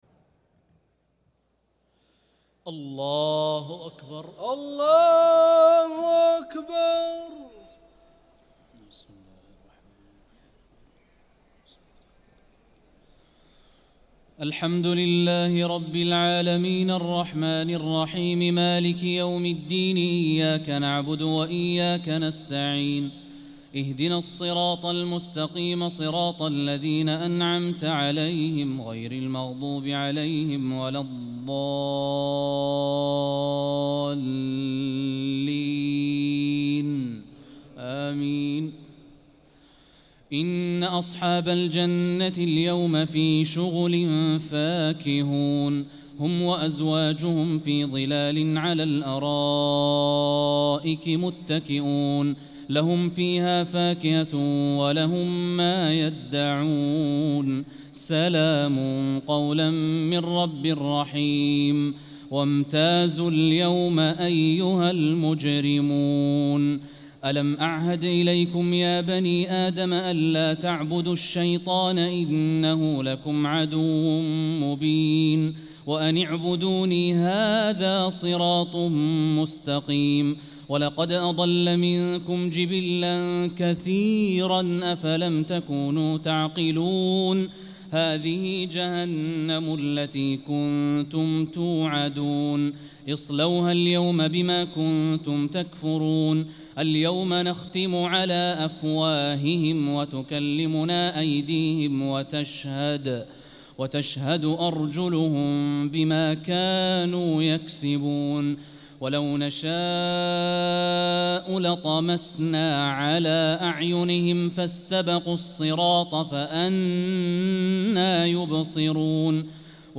Ramadan Tarawih
صلاة التراويح